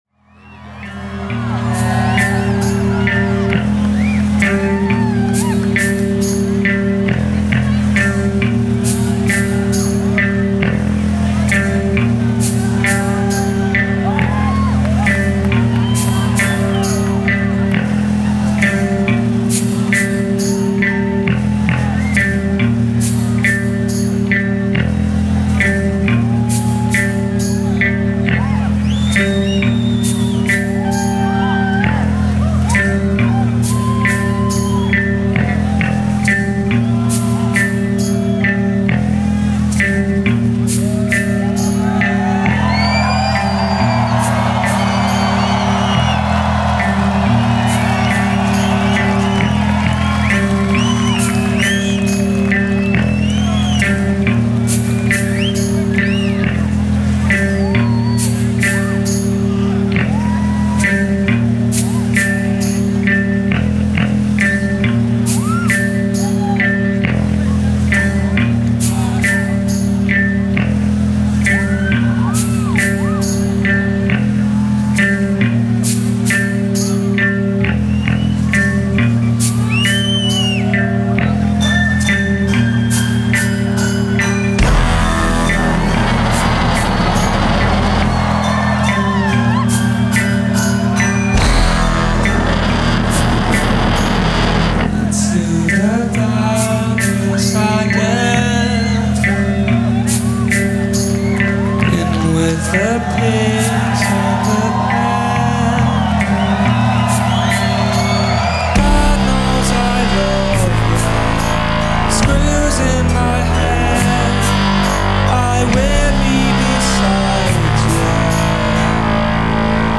recorded and broadcast live